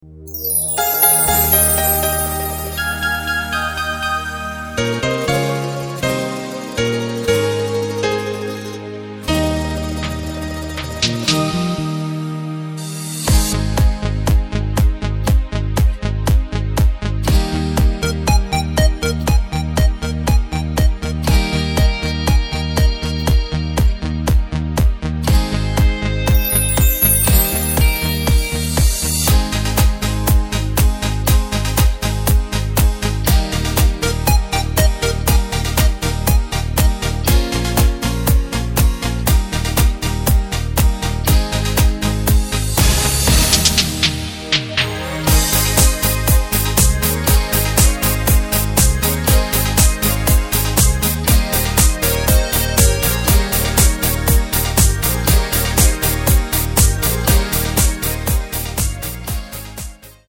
Takt: 4/4 Tempo: 120.00 Tonart: Ab
Discofox